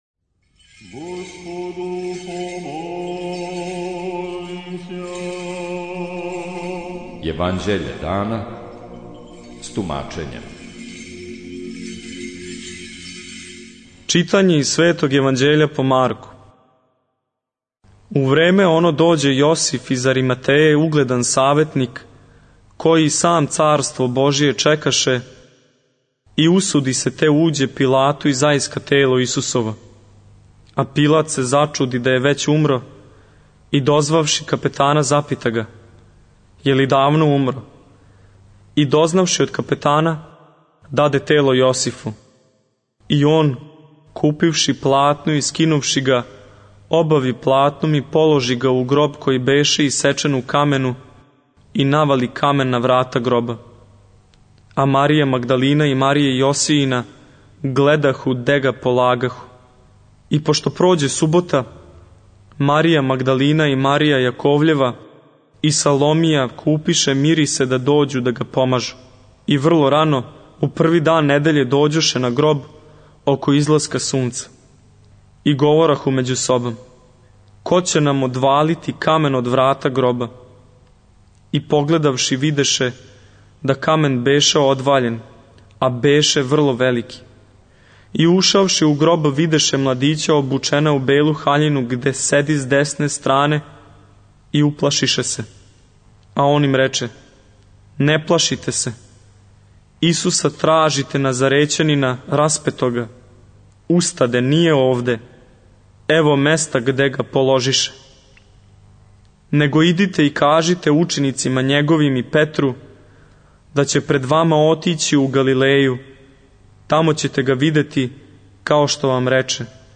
Читање Светог Јеванђеља по Марку за дан 02.02.2023. Зачало 57.